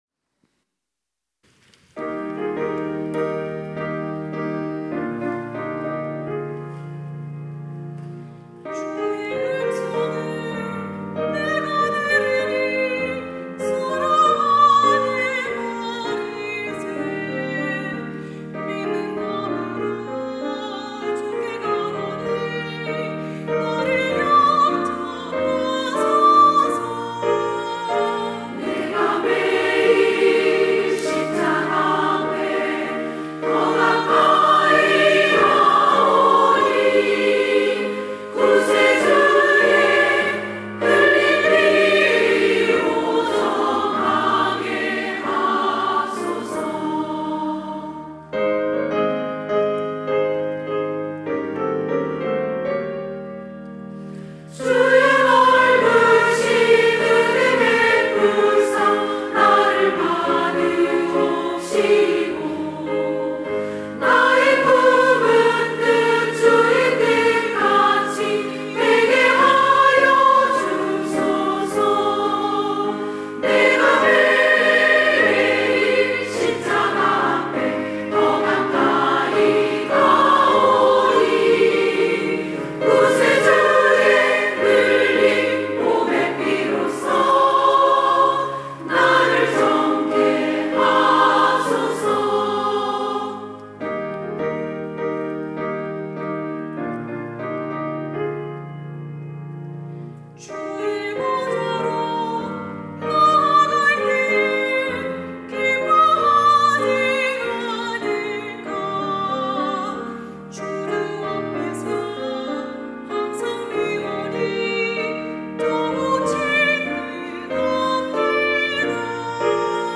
찬양대 여전도회